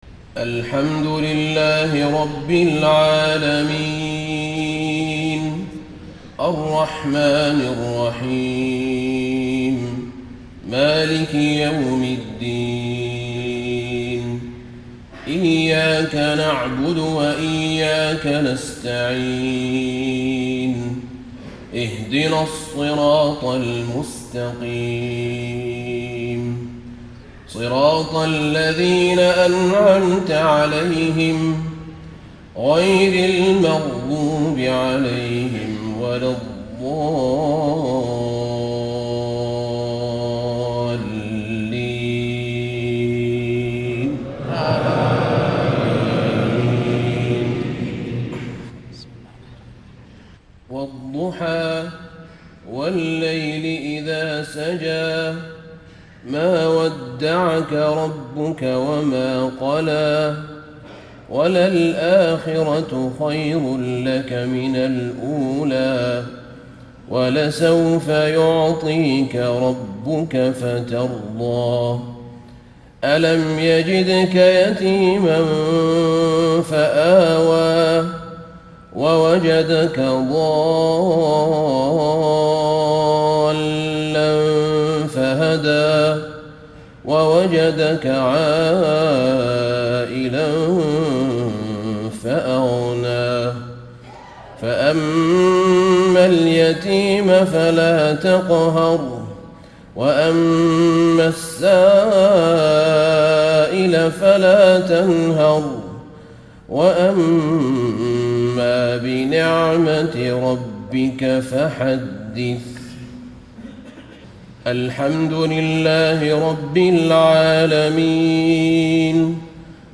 صلاة المغرب 1 - 3 - 1436 تلاوة من سورتي الضحى والشرح .